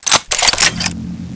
Index of /server/sound/weapons/tfa_cso/laserfist
draw.wav